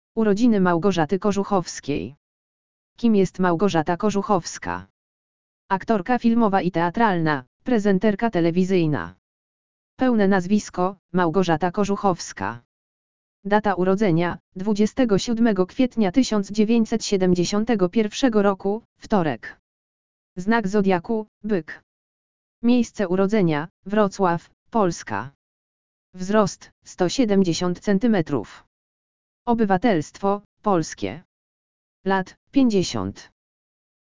audio_lektor_urodziny_malgorzaty_kozuchowskiej.mp3